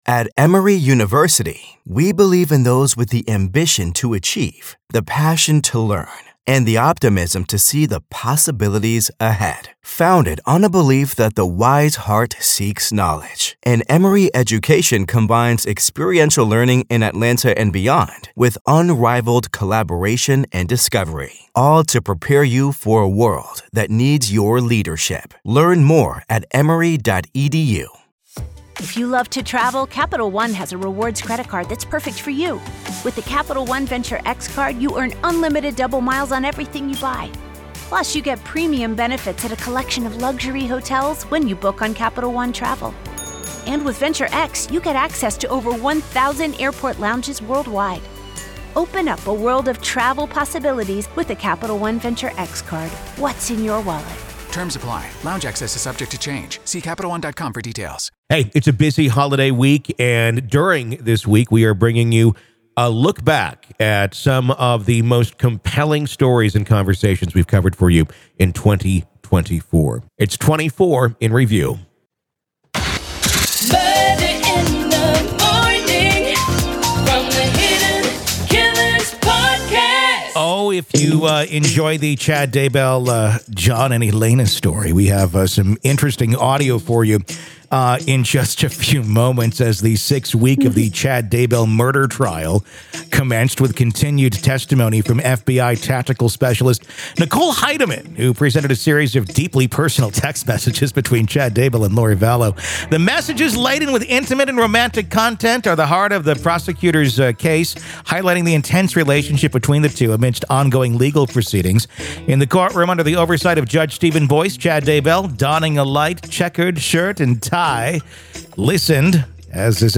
Each episode navigates through these stories, illuminating their details with factual reporting, expert commentary, and engaging conversation.